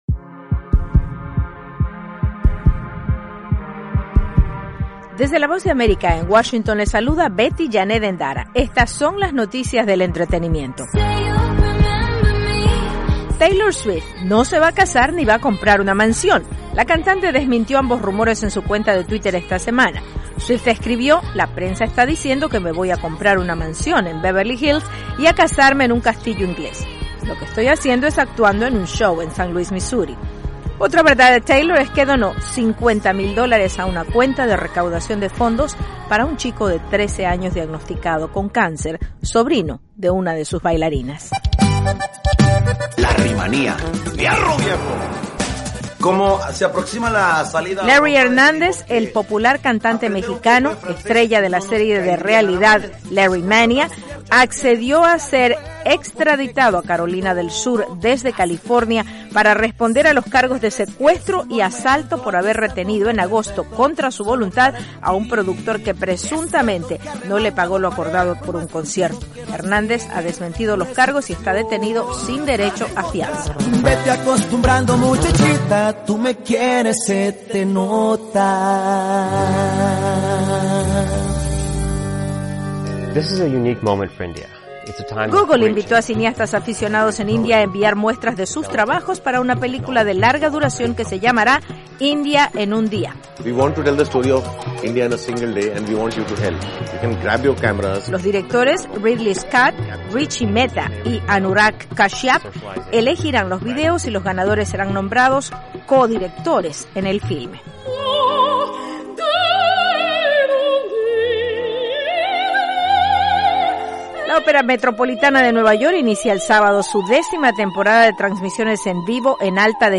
Taylor Swift desmiente rumores, Larry Hernández extraditado a Carolina del Sur, Google anuncia proyecto de cine, la Ópera Metropolitana inicia nueva temporada, concurso de música de Chopin comienza en Polonia. Informa